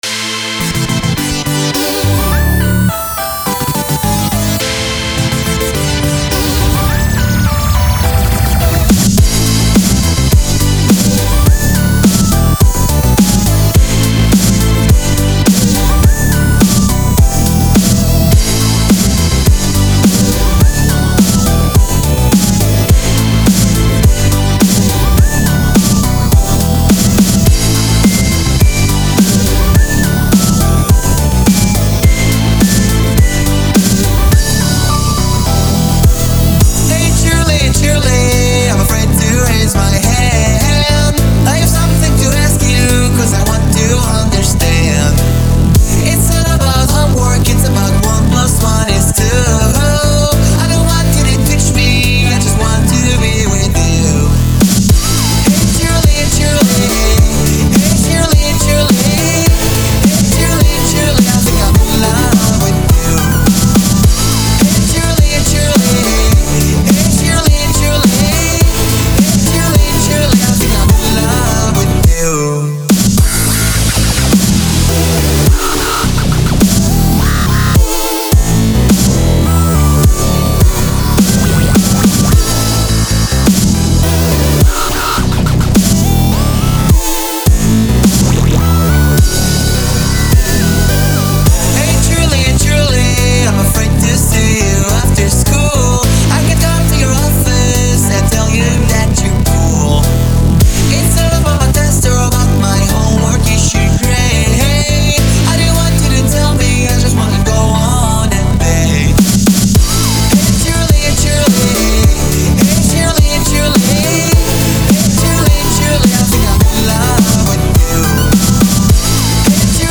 4299 song